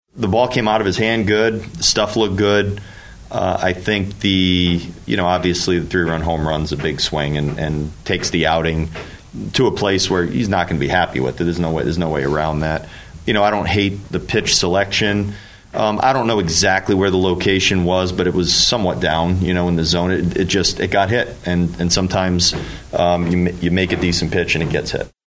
Grisham ended up with two homers on the night and four R-B-I. Taj Bradley suffers the loss for Minnesota, manager Rocco Baldelli on the outing for starter Taj Bradley.